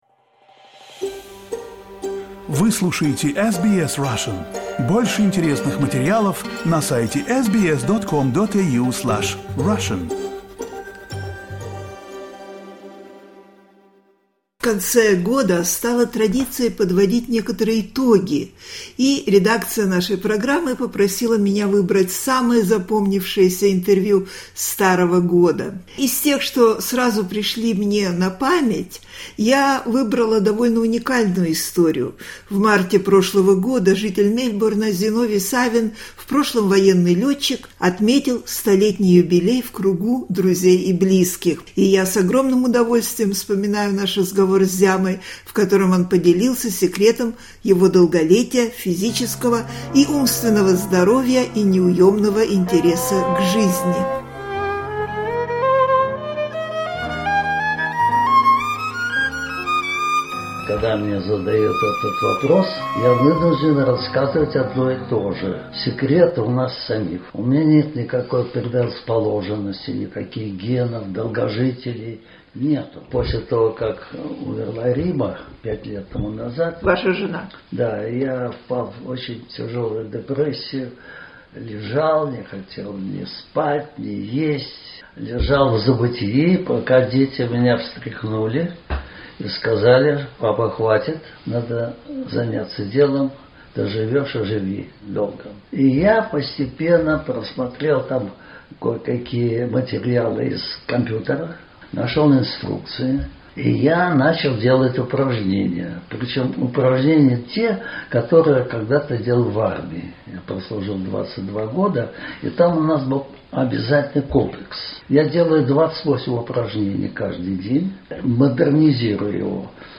В конце года мы вспоминаем любимые интервью 2025.